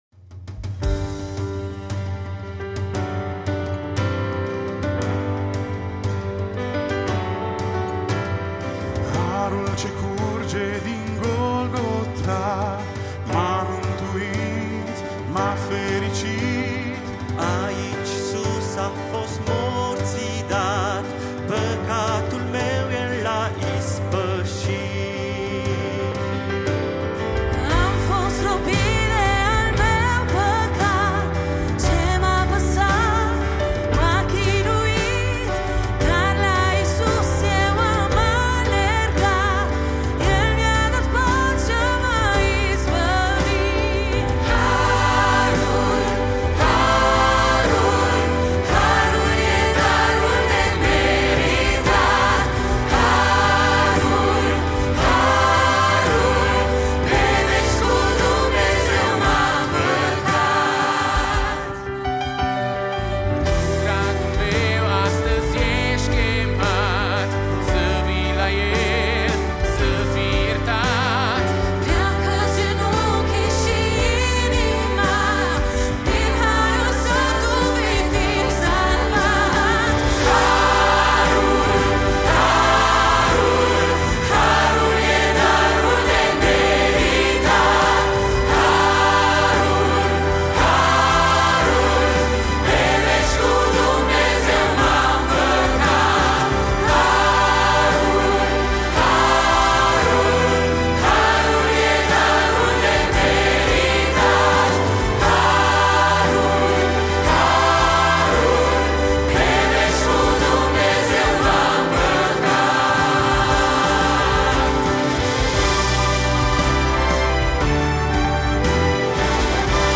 Live